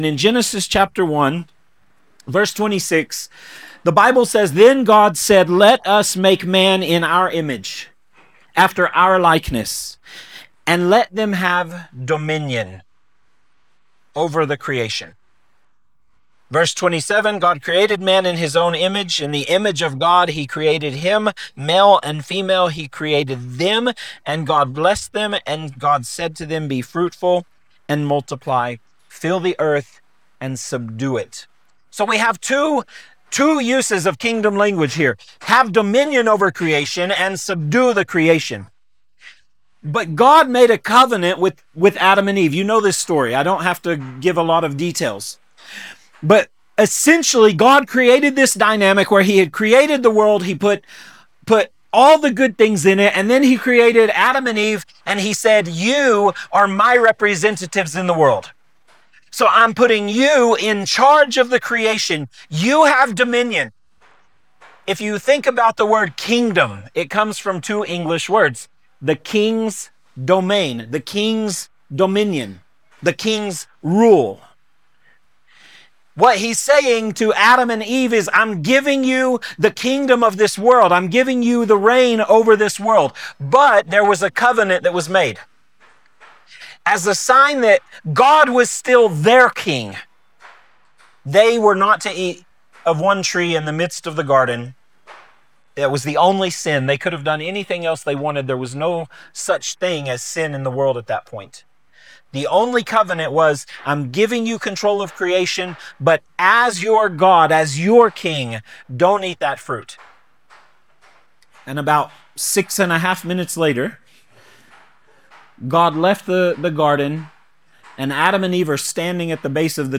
Sermons | Sozo Ministries